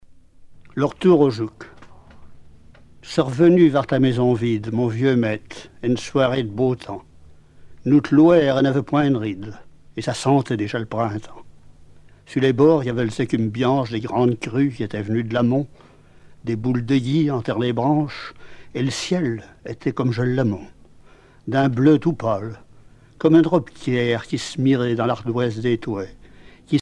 Genre poésie
Catégorie Récit